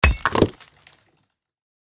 axe-mining-stone-5.ogg